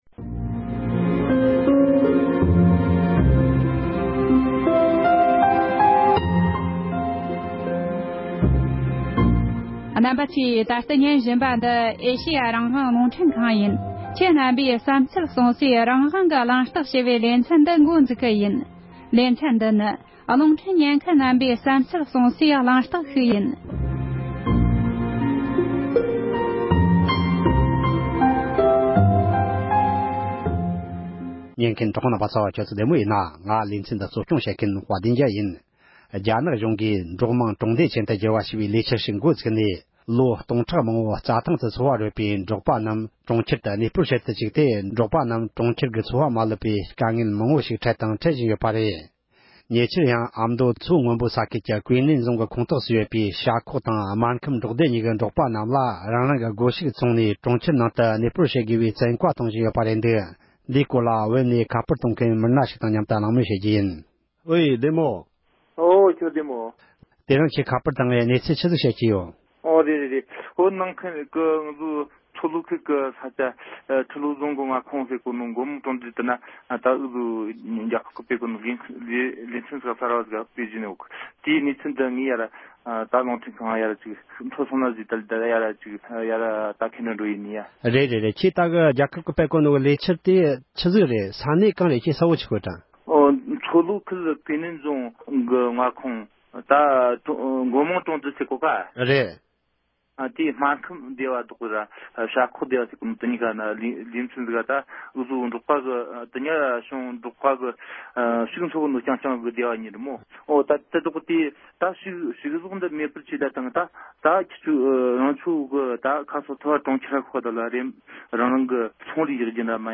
བོད་ནས་ཞལ་པར་གཏོང་མཁན་བོད་མི་ཞིག་དང་ལྷན་དུ་གླེང་མོལ་ཞུས་པར་གསན་རོགས་གནོངས༎